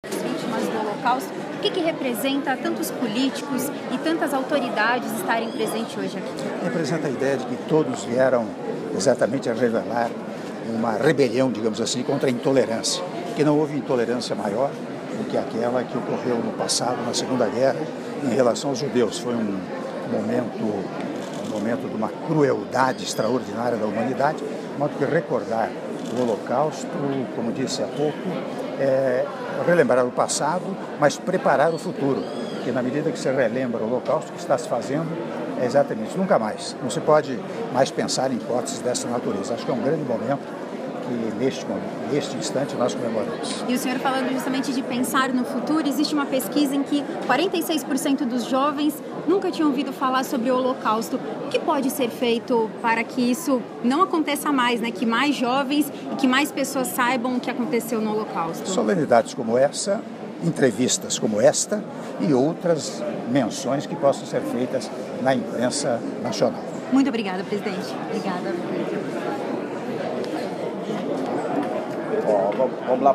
Áudio da entrevista do presidente da República, Michel Temer, após cerimônia do Dia Internacional em Memória das Vítimas do Holocausto - São Paulo/SP -(01min13s)